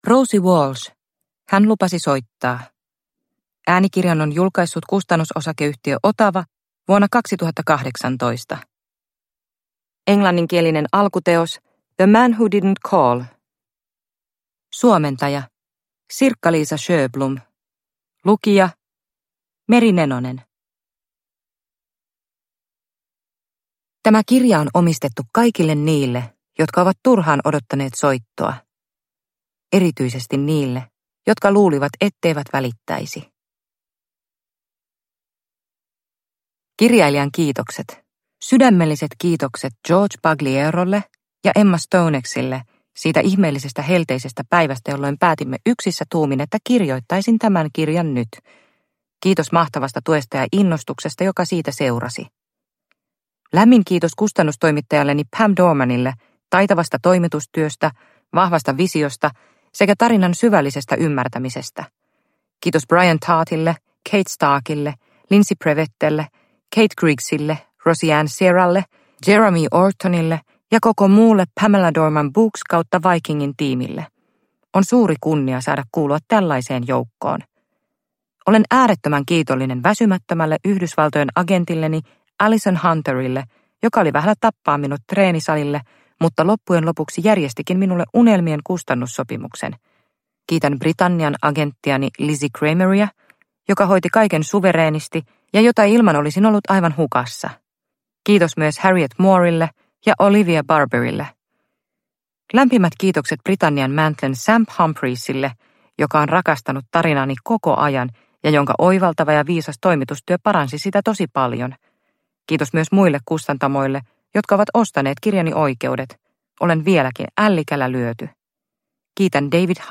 Hän lupasi soittaa – Ljudbok – Laddas ner